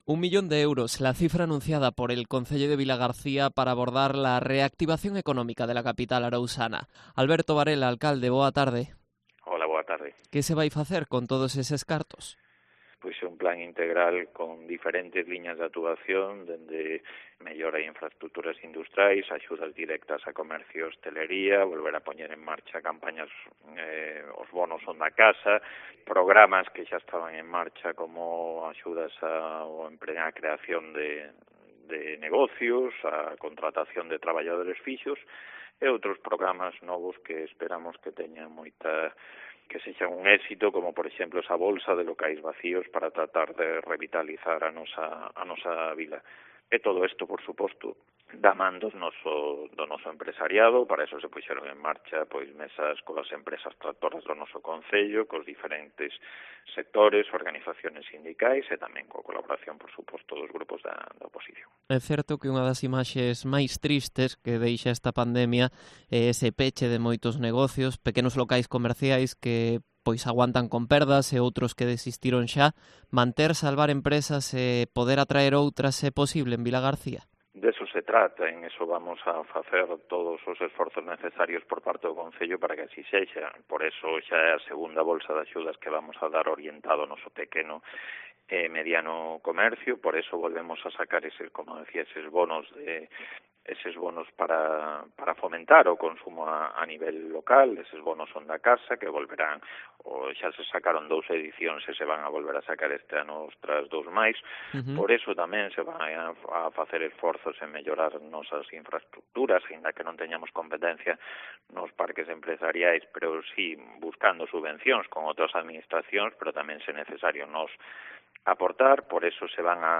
Entrevista a Alberto Varela, alcalde de Vilagarcía de Arousa